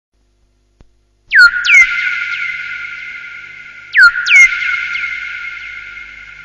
Vissla, Naturljud, Android